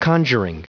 Prononciation du mot conjuring en anglais (fichier audio)
Prononciation du mot : conjuring